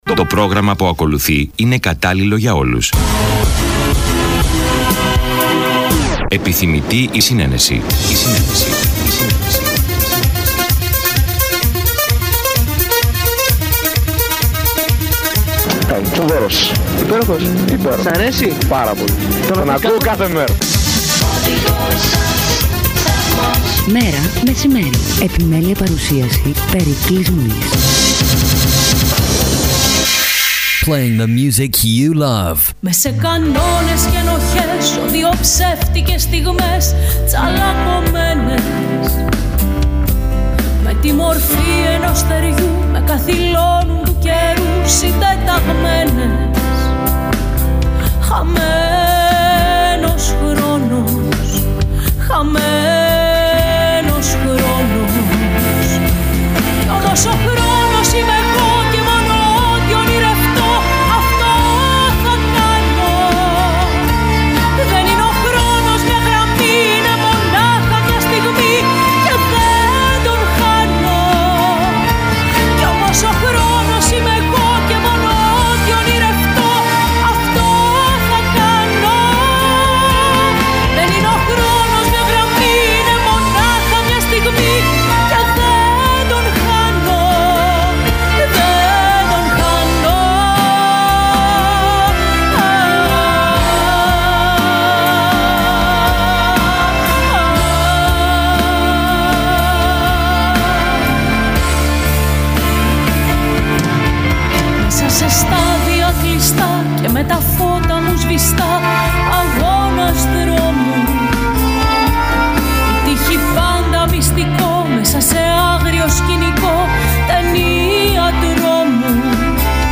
«Μέρα μεσημέρι» Καθημερινό ραδιοφωνικό μαγκαζίνο που ασχολείται με ρεπορτάζ της καθημερινότητας, παρουσιάσεις νέων δισκογραφικών δουλειών, συνεντεύξεις καλλιτεχνών και ανάδειξη νέων ανθρώπων της τέχνης και του πολιτισμού.